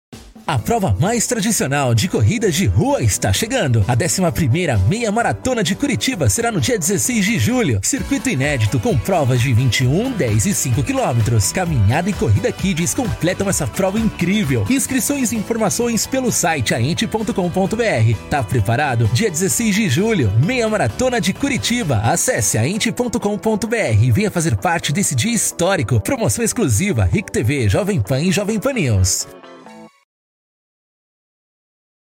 CHAMADA - CORRIDA DE RUA:
Impacto
Animada